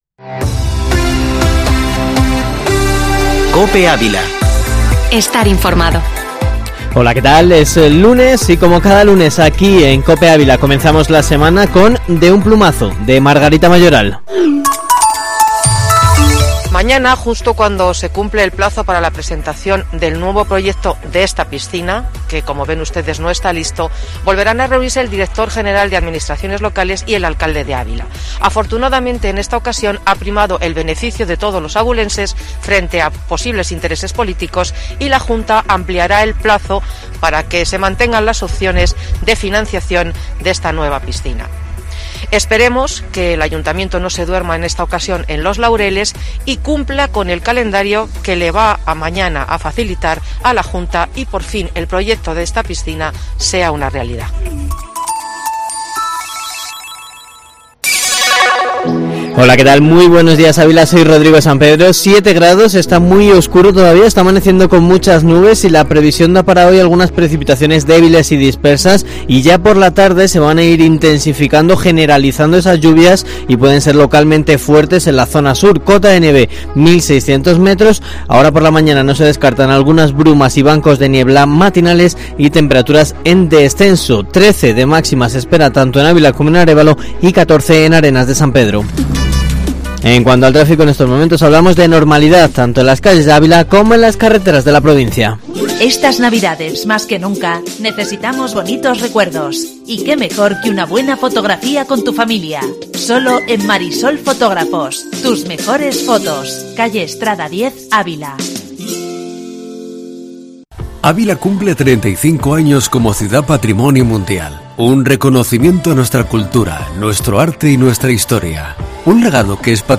Informativo matinal Herrera en COPE Ávila 14/12/2020